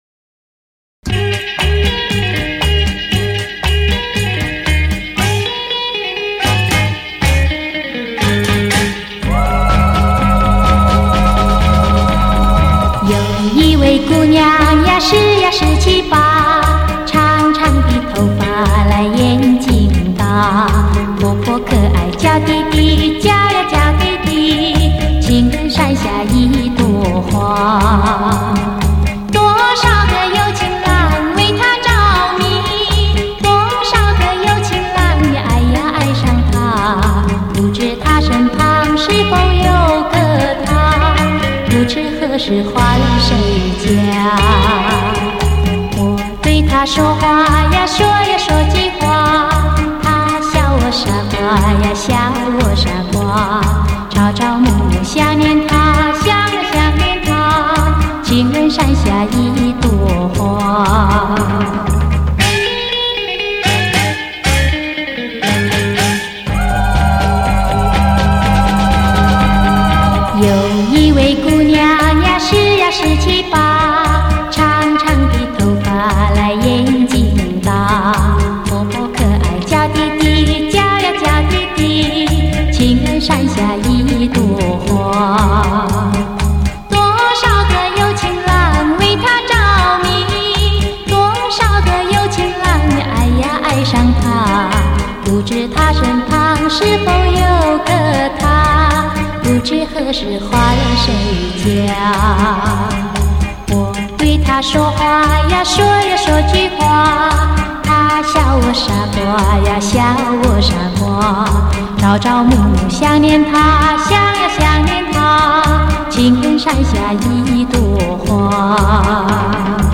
数码调音录制